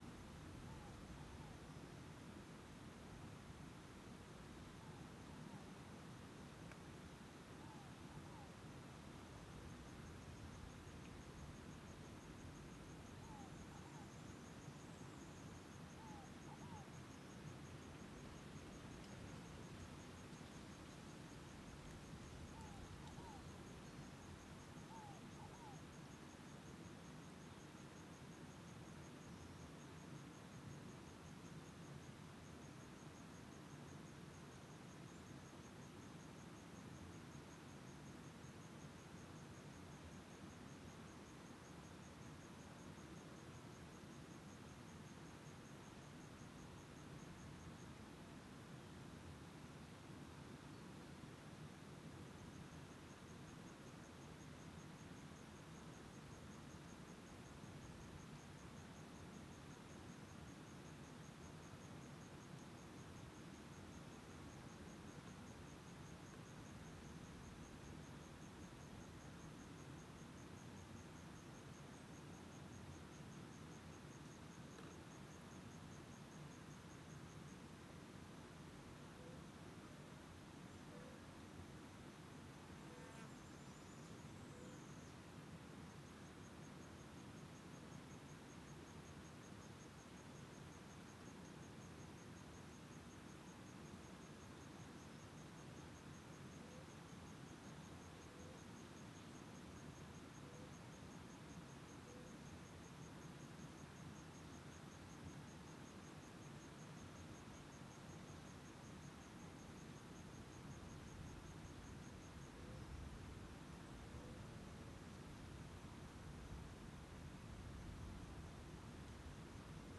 Beira de estrada perto de mata ambiente tranquilo com passaros e grilos Ambiente tranquilo , Estrada de terra , Grilo , Manhã , Mata fechada , Pássaros , Terra Ronca Goiás
Surround 5.1